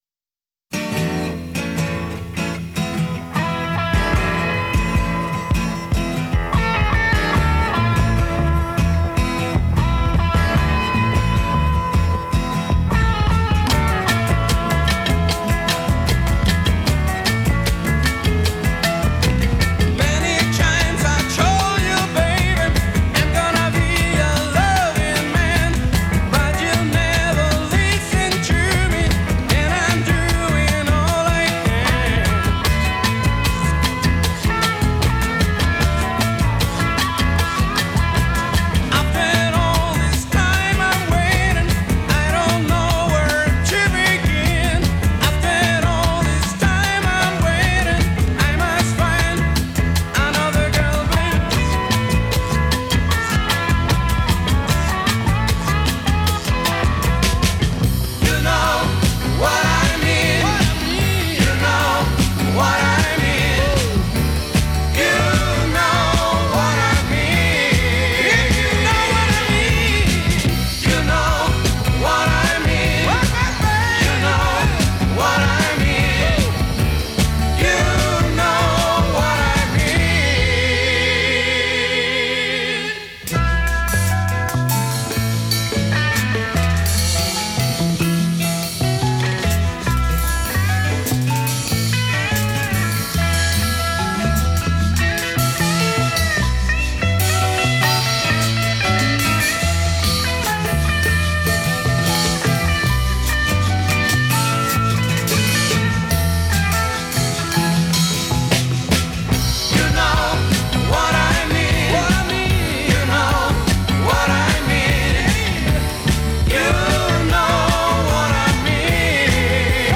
играющая в стиле R & B и Funk Rock